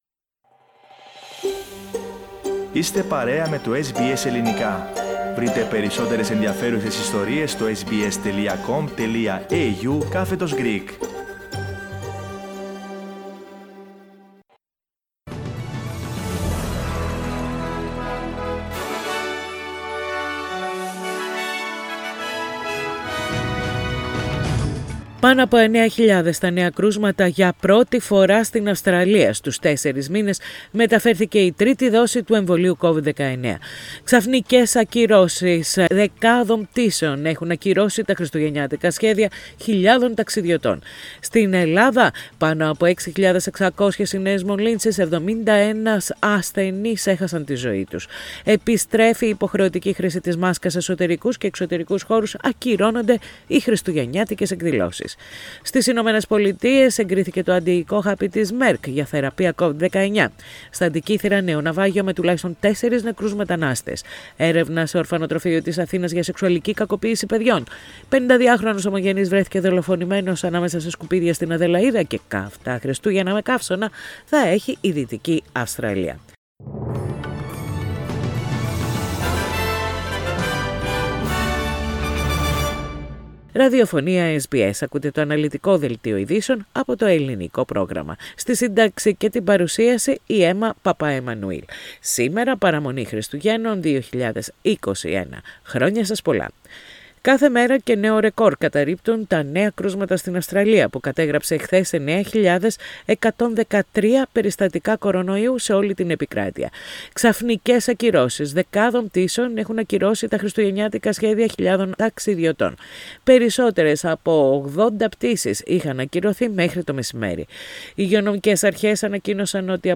The main news bulletin of Christmas Eve, from Australia, Greece, Cyprus and the international arena.